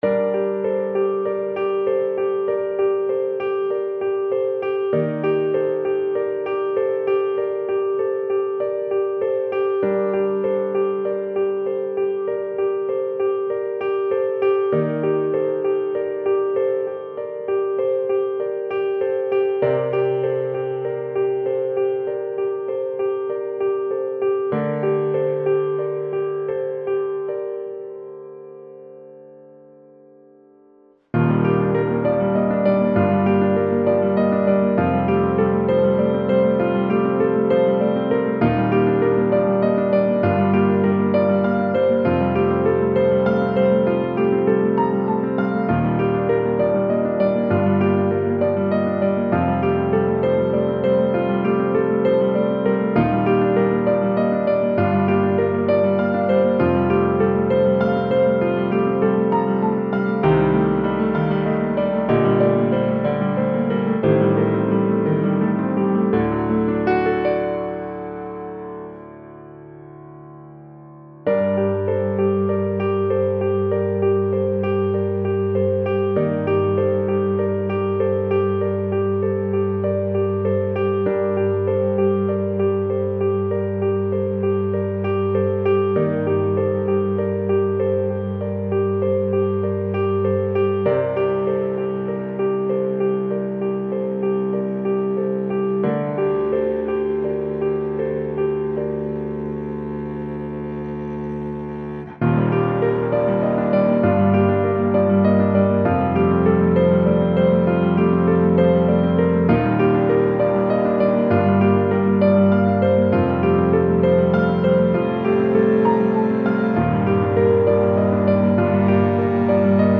Largo [0-10] melancolie - piano - - -